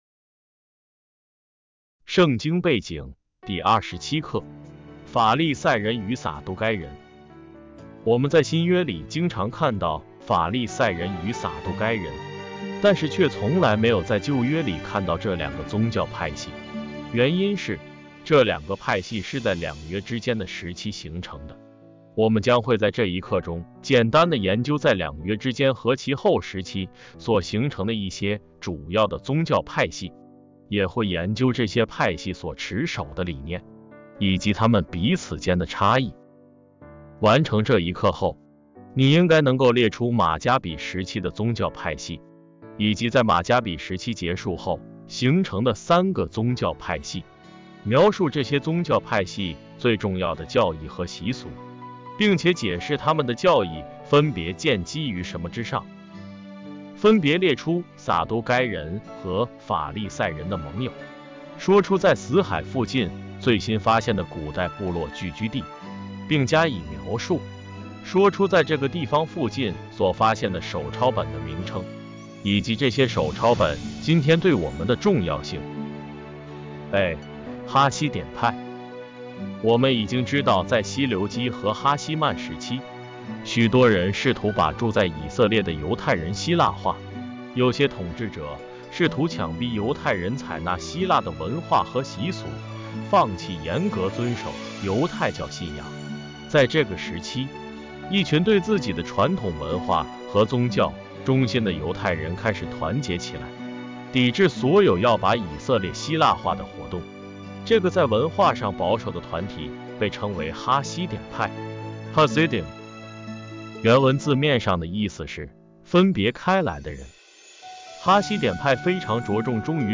圣经背景-第二十七课（音乐）.mp3